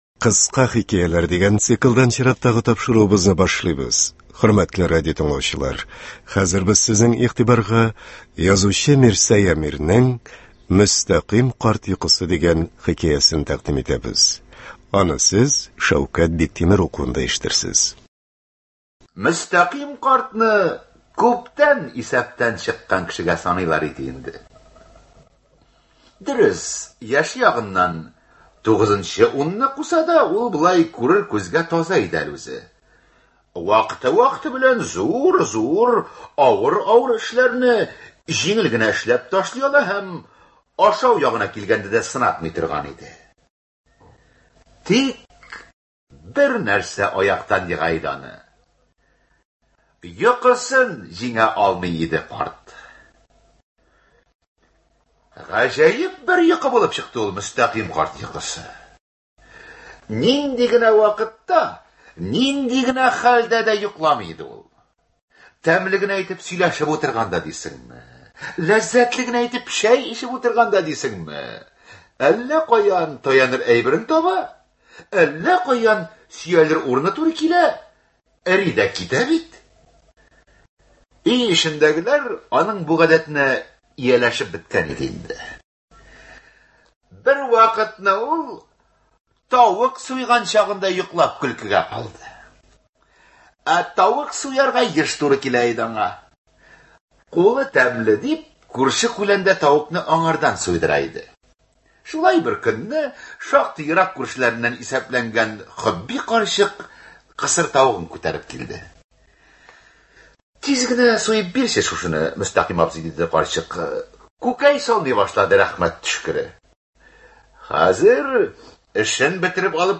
Күренекле язучы Мирсәй Әмирнең «Мөстәкыйм карт йокысы» хикәясен тыңларга чакырабыз. Ул СССРның халык артисты Шәүкәт Биктимеров язмасында яңгырый.